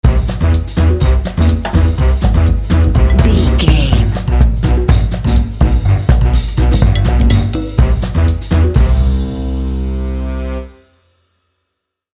Ionian/Major
bouncy
energetic
happy
joyful
groovy
piano
drums
brass
percussion
60s
jazz
bossa